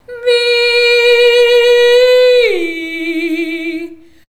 CLASSIC.wav